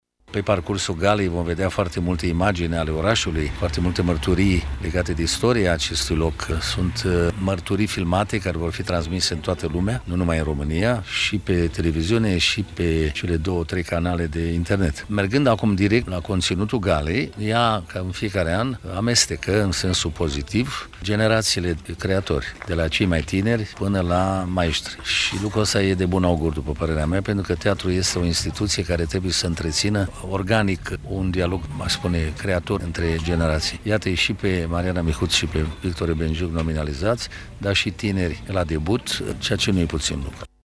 Ion Caramitru Preşedintele UNITER într-o declaraţie pentru Radio România Cluj: